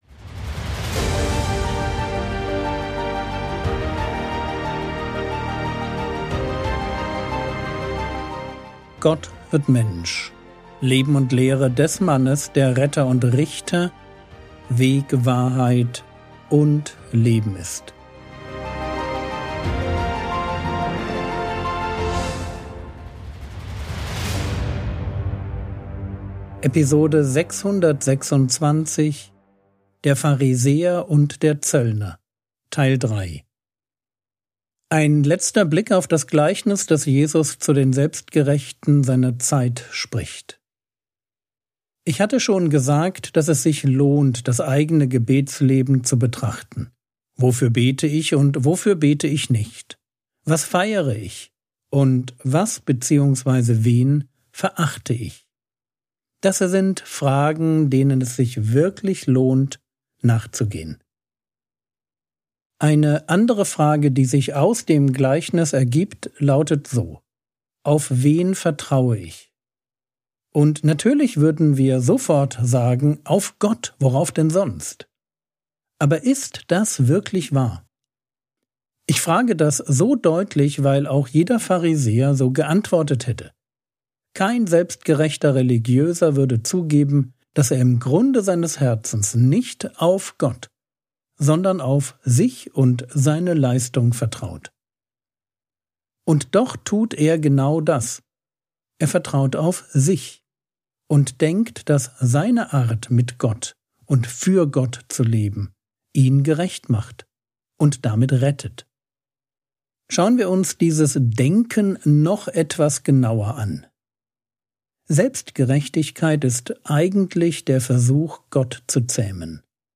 Frogwords Mini-Predigt Podcast - Episode 626 | Jesu Lehre und Leben | Free Listening on Podbean App